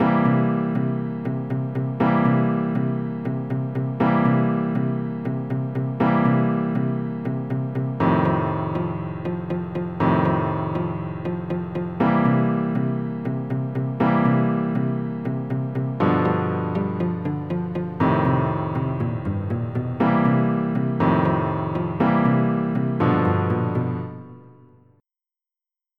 MIDI Music File
Type General MIDI (type 1)